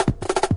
MoonWalk Fill.wav